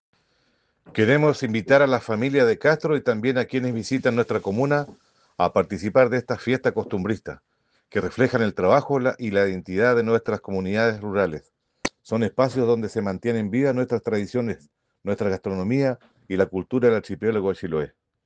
El alcalde de Castro, Baltazar Elgueta , invita a la comunidad a ser parte de estas actividades.
CUNA-ALCALDE-ELGUETA-FIESTAS-COSTUMBRISTAS-MARZO.mp3